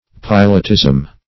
Search Result for " pilotism" : The Collaborative International Dictionary of English v.0.48: Pilotism \Pi"lot*ism\, Pilotry \Pi"lot*ry\, n. Pilotage; skill in the duties of a pilot.